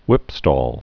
(wĭpstôl, hwĭp-)